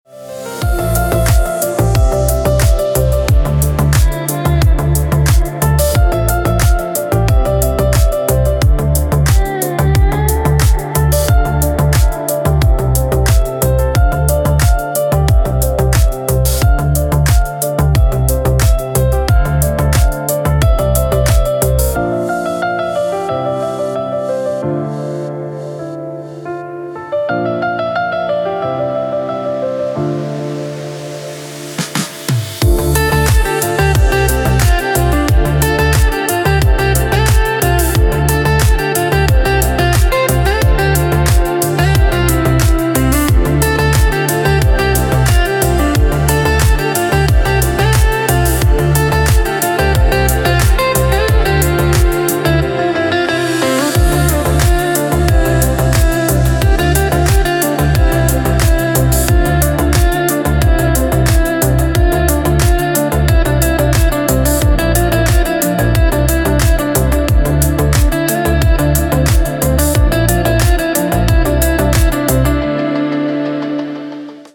Прекрасная отбивка без слов